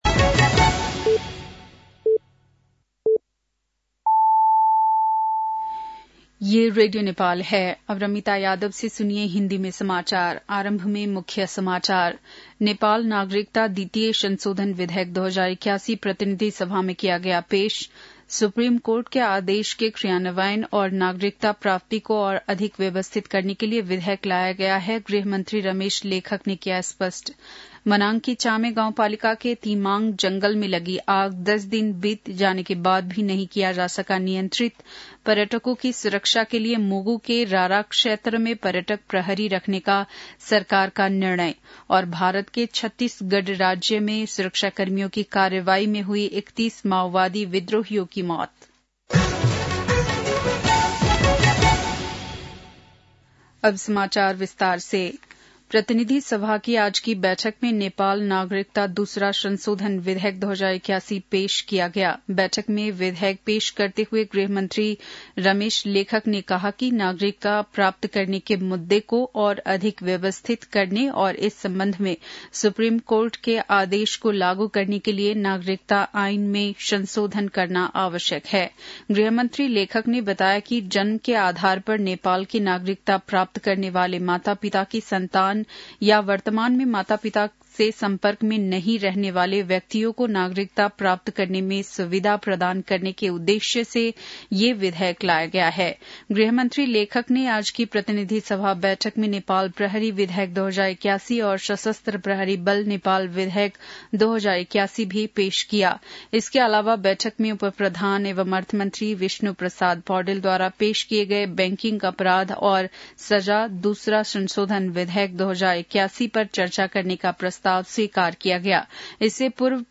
बेलुकी १० बजेको हिन्दी समाचार : २८ माघ , २०८१